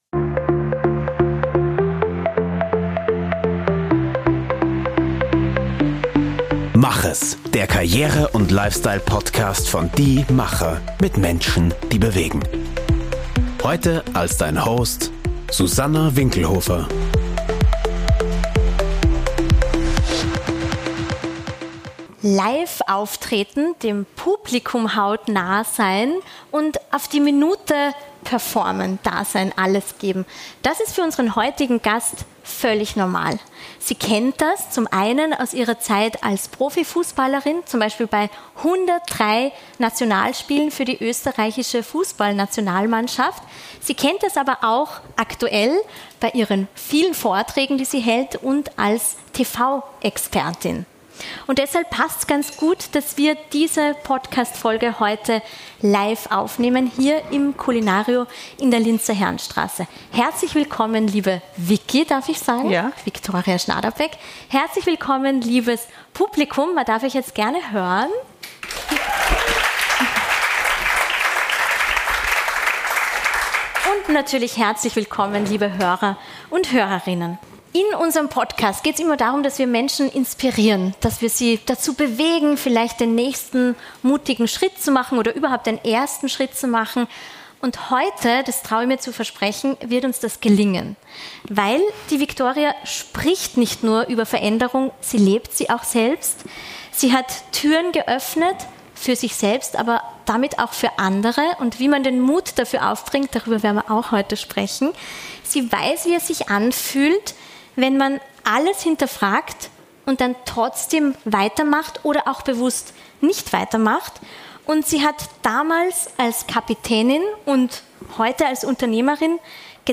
Und das hat sie auch bei unserem Live-Podcast erlebt.
Im Kulinario in der Linzer Herrenstraße.
In dieser inspirierenden Podcastfolge – live vor Publikum aufgenommen – gibt sie praktische Tipps, wie Menschen den Mut für den nächsten Schritt finden.